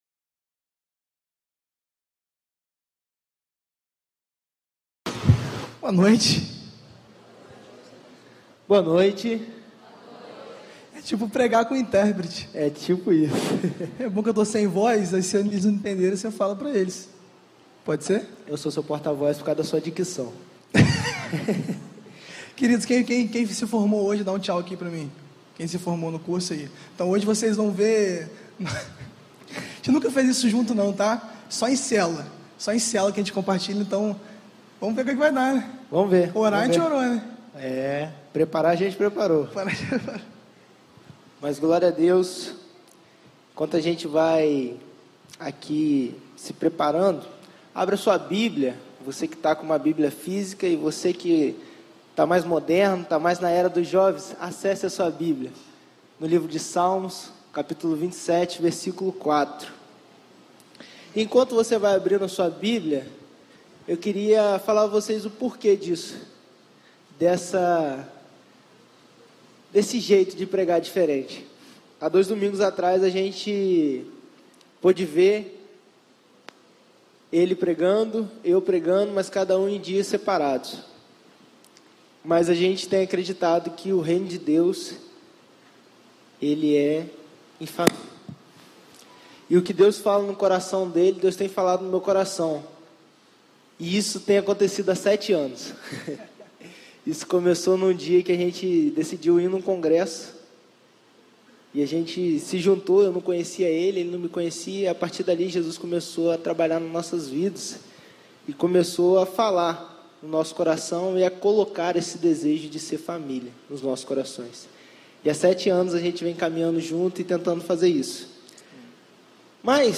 Mês da Juventude
Primeira Igreja Batista do IPS